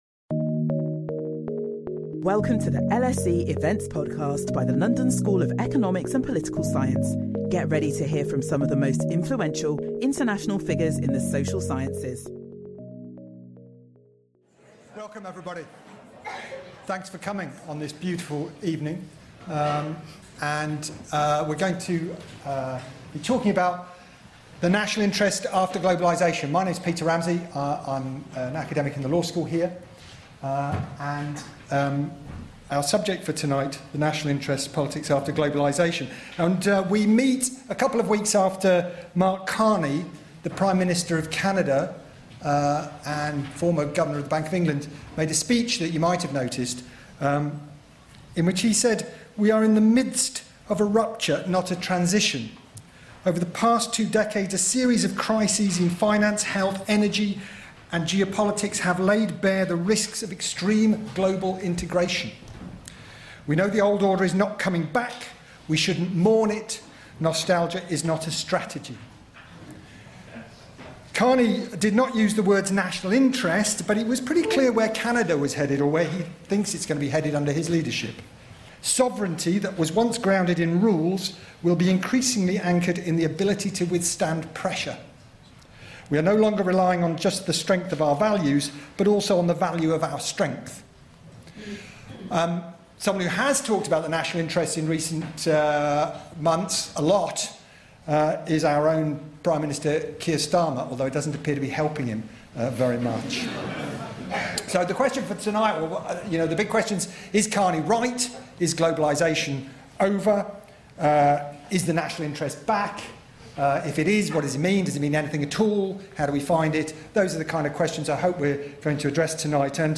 Our panel of three experts will discuss whether there really is such a thing as the national interest, whether it really is back, who decides what it is, and what effects thinking in terms of national interest may have on politics both within individual states and between them.